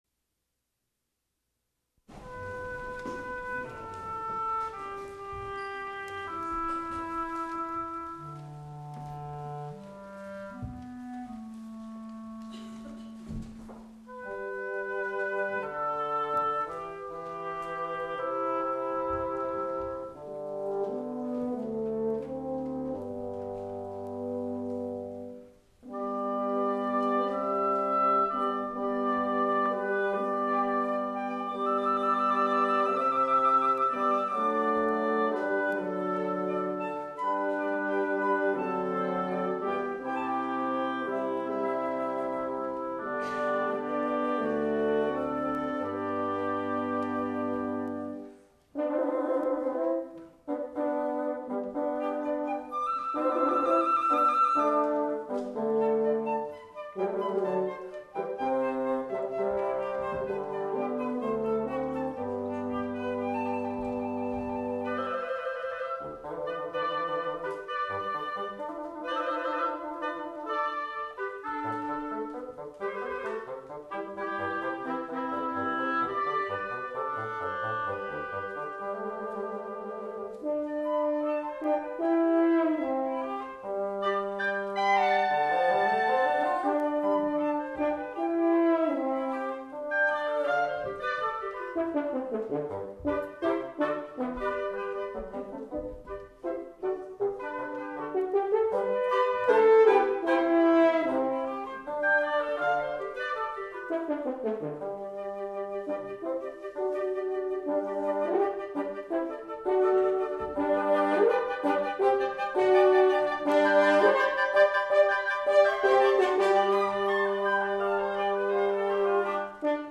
for Woodwind Quintet (1988)